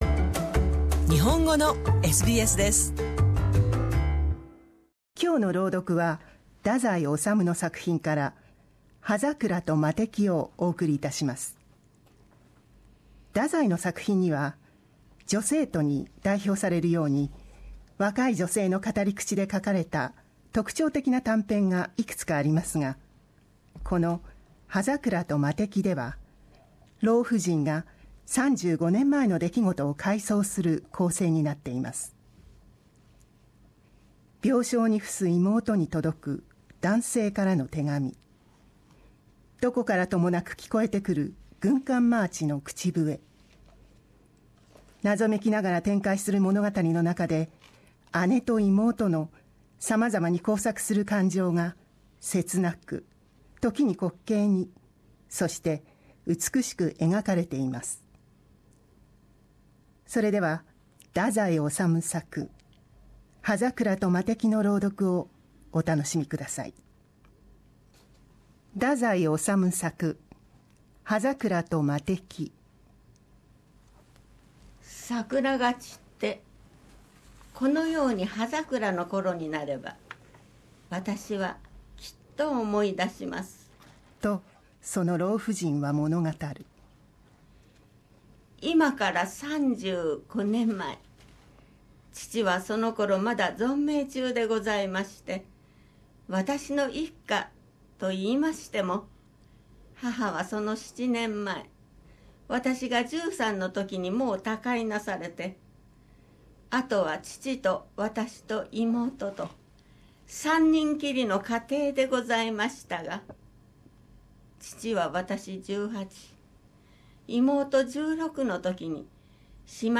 シドニーで活動する朗読の会「声」が、文豪・太宰治の短編小説「葉桜と魔笛」をお送りします。